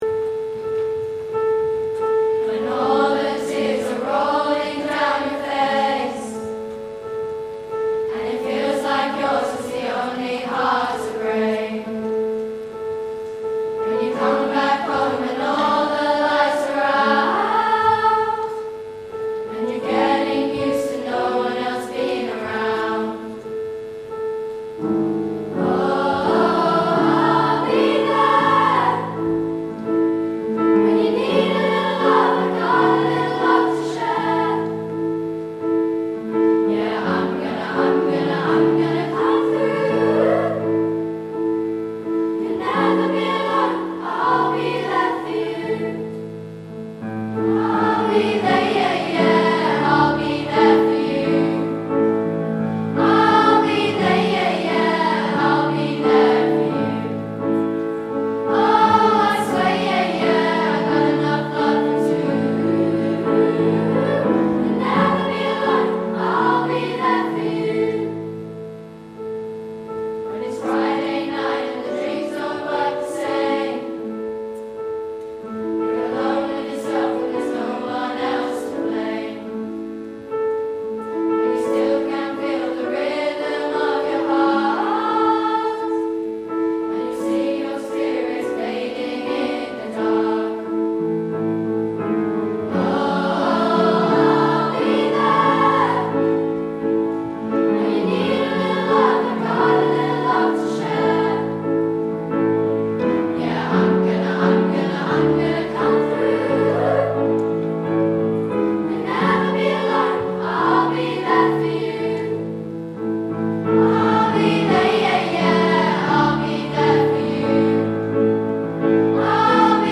Secondary Choir